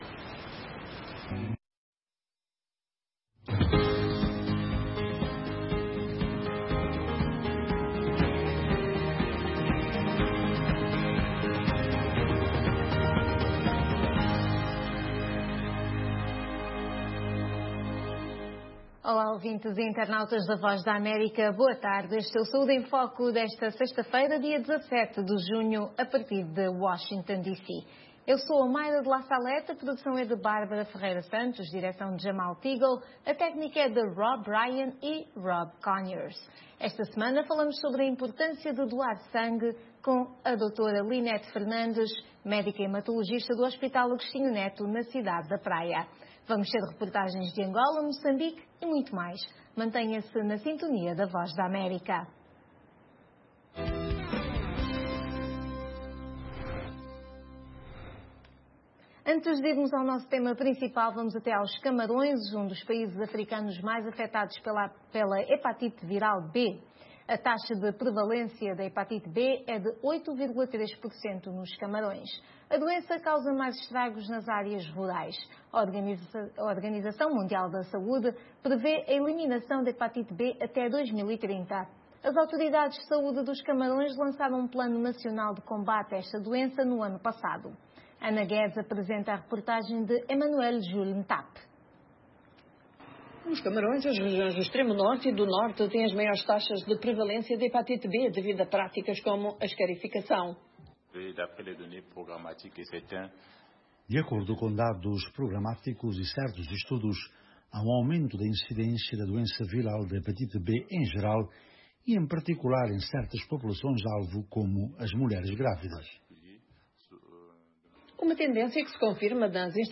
O programa é transmitido às sextas-feiras às 16h30 UTC. Todas as semanas falamos sobre saúde, dos Estados Unidos da América para o mundo inteiro, com convidados especiais no campo social e da saúde.